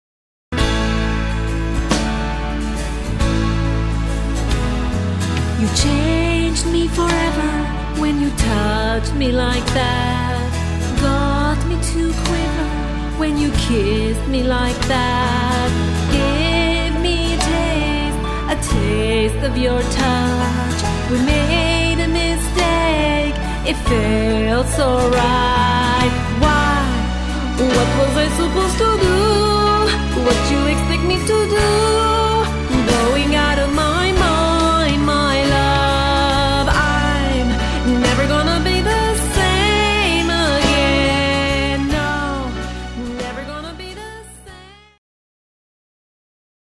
Category: Pop